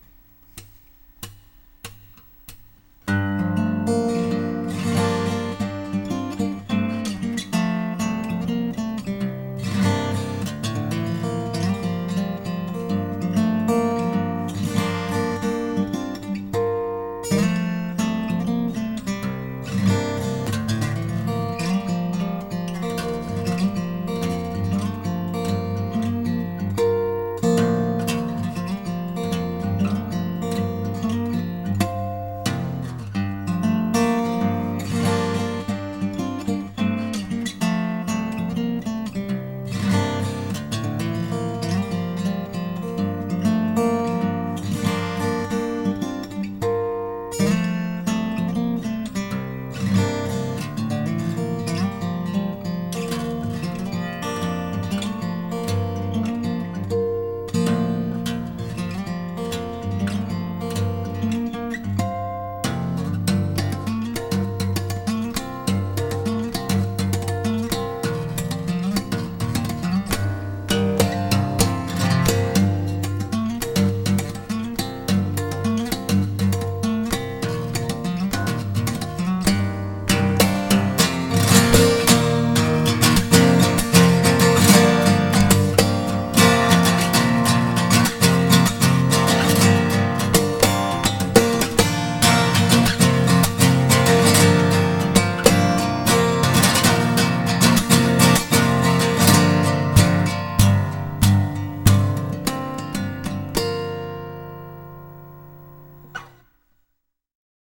全二音下げ。変則。クリックなしで一発録り。
飲んだ勢いで録ってみたけど